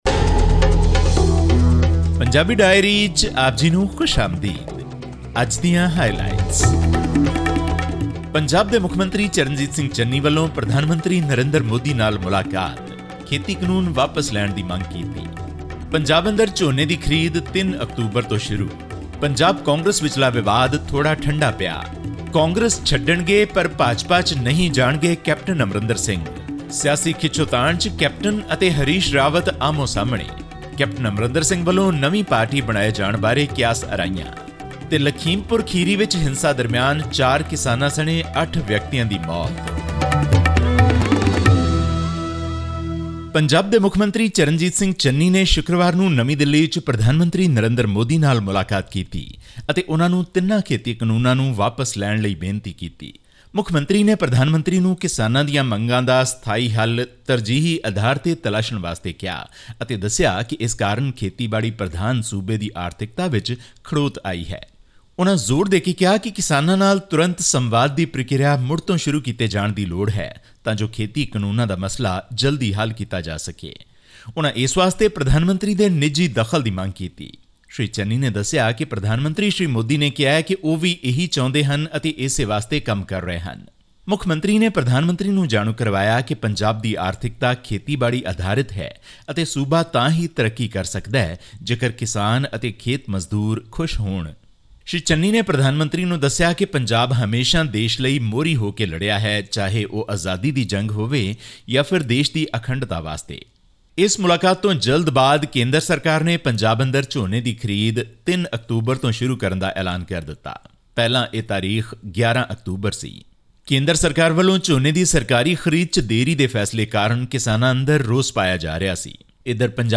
At least eight people, including four farmers, were killed after violence erupted during a protest against the controversial farm laws in the Uttar Pradesh town of Lakhimpur Kheri on 3 October. This and more in our weekly news update from India.